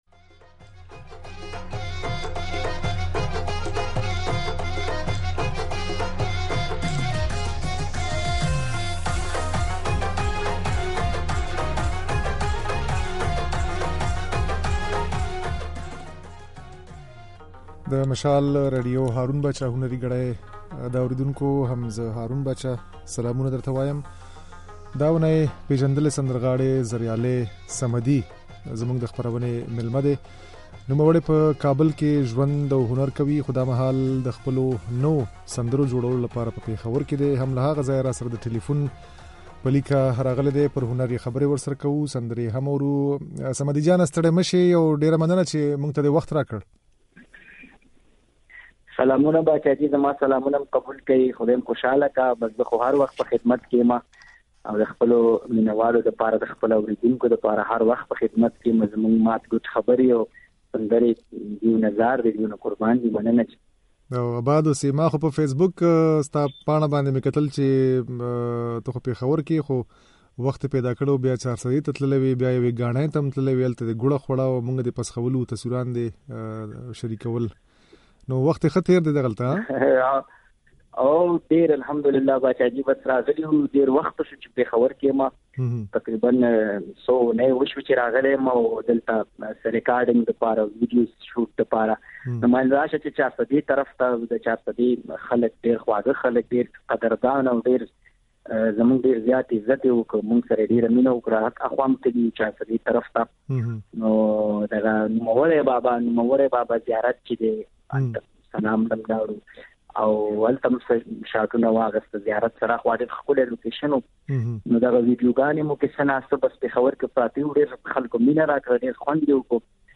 دا اوونۍ مو "هارون باچا هنري ګړۍ" خپرونې ته پېژندلی سندرغاړی زريالي صمدي مېلمه کړی وو.
صمدي وايي، د پېښور په بېلا بېلو سټوډيوګانو کې يې د نويو سندرو رېکارډول پيل کړي او په پښتونخوا کې به ورته ويډيوګانې هم جوړوي. د ده په خبره، د البم ډېری سندرې يې مستې دي خو پلان لري چې څه موده وروسته غزلونه هم ثبت کړي. د زريالي صمدي دا خبرې او ځينې سندرې يې د غږ په ځای کې اورېدای شئ.